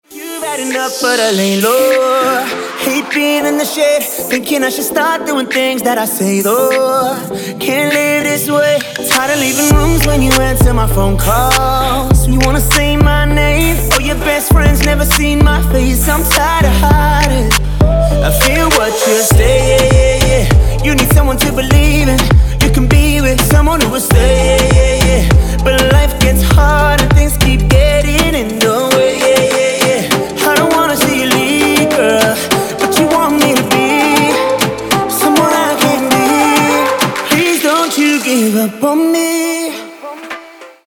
• Качество: 192, Stereo
поп
мужской вокал
dance
vocal